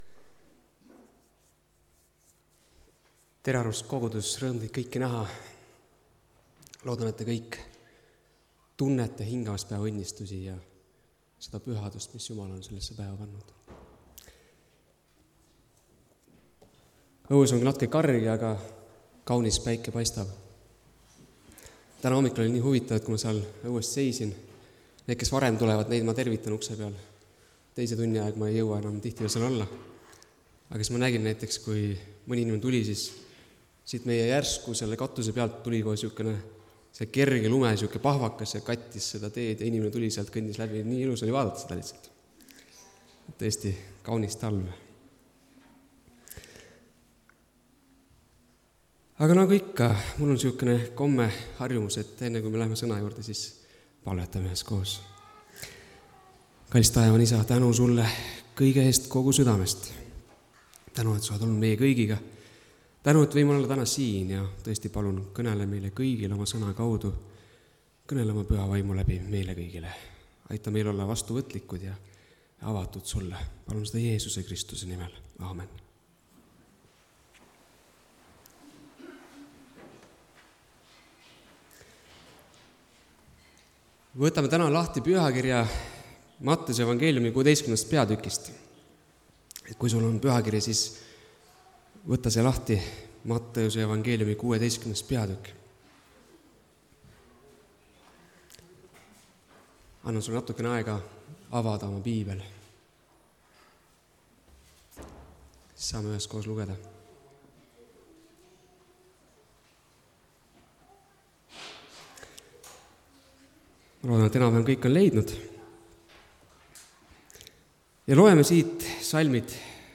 Messias (Tallinnas)
Jutlused